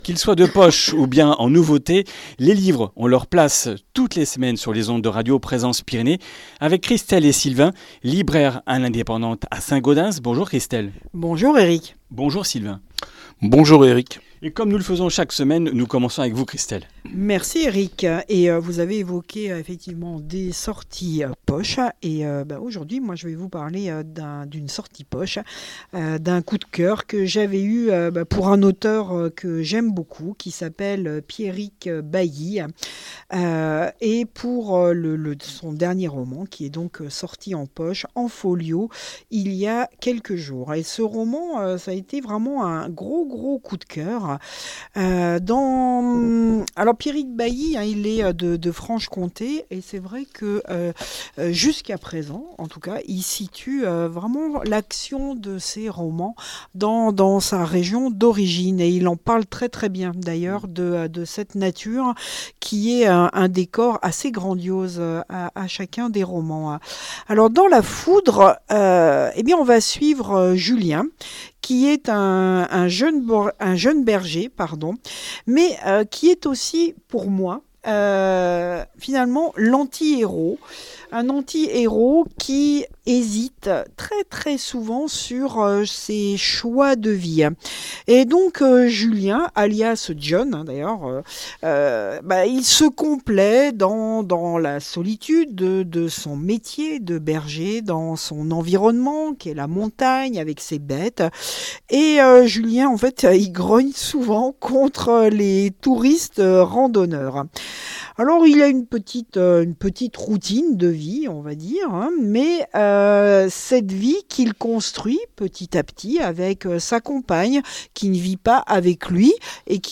Comminges Interviews du 13 juin